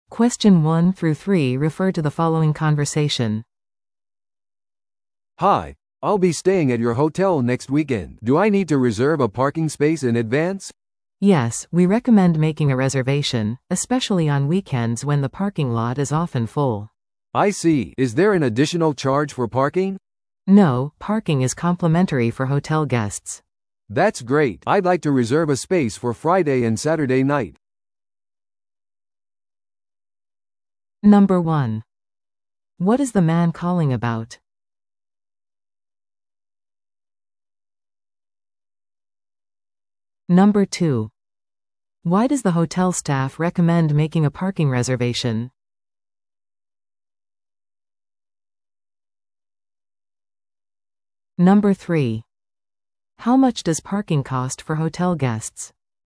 PART3は二人以上の英語会話が流れ、それを聞き取り問題用紙に書かれている設問に回答する形式のリスニング問題です。